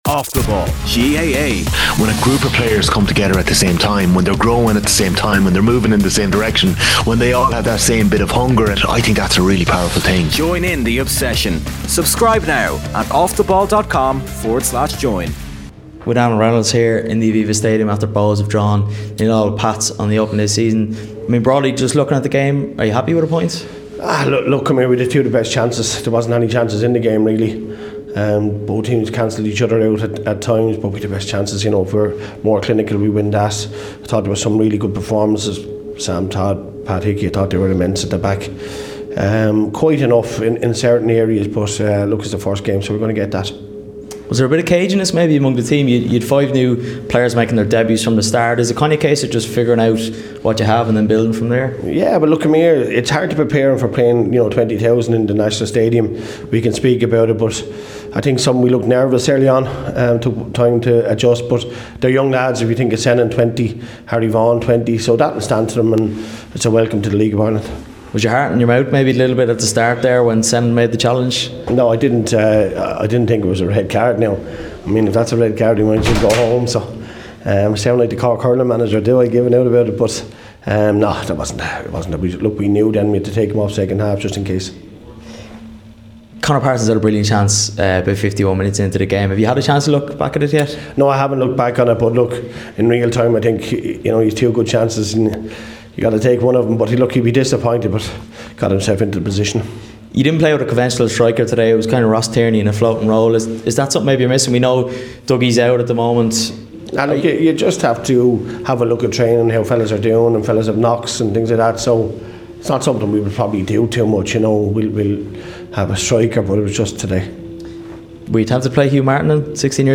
Dublin Derby Reaction: "If that's a red card we might as well go home" | Bohs vs St. Patricks | Live from the Aviva